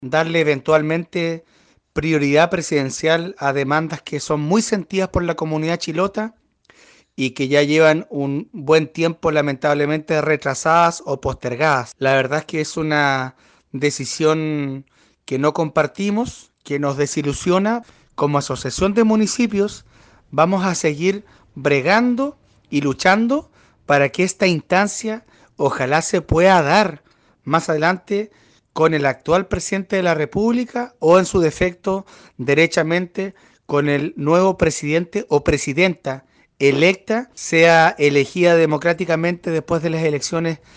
Igualmente, el alcalde de Quéilen Marcos Vargas, expresó su molestia por la respuesta en contrario que el presidente Piñera le da a las demandas de la provincia.